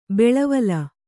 ♪ beḷavala